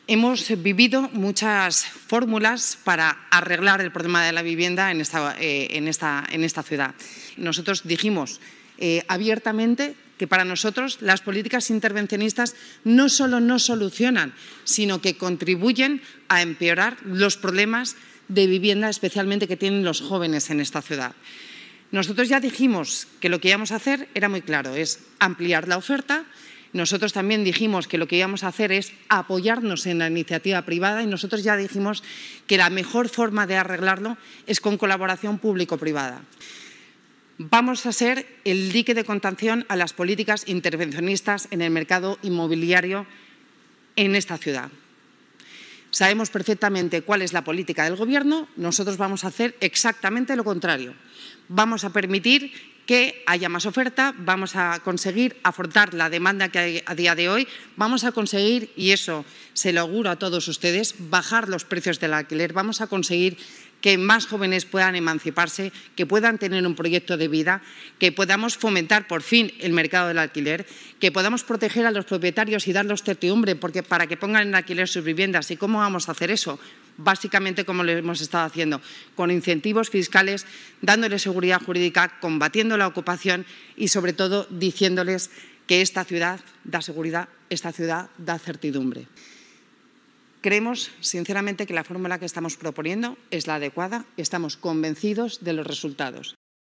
Begoña Villacís, vicealcaldesa, sobre los objetivos de la estrategia global de vivienda del ayuntamiento de Madrid